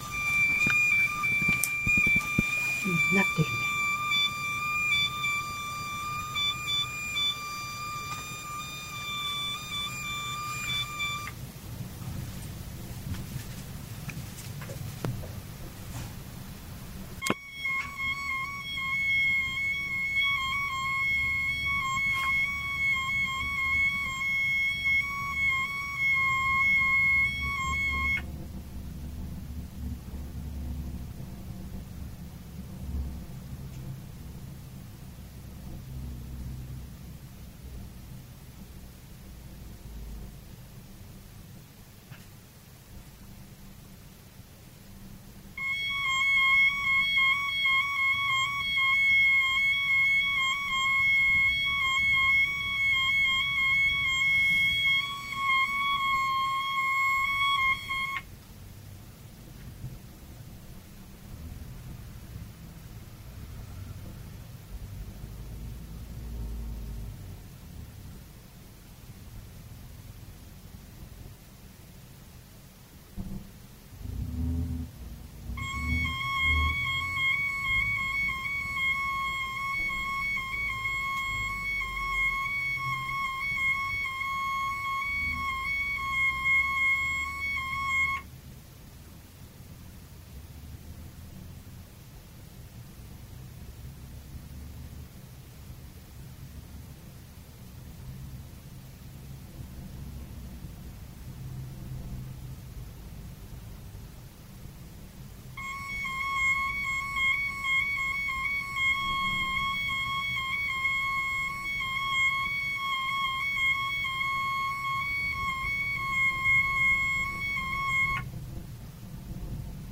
モールス信号のTV音声＆オーディオへの混入例：（ステレオに繋いで聞いて！）
（隣家無線局過大出力） ●モールス送信
●FT-8混信